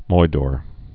(moidôr, moi-dôr)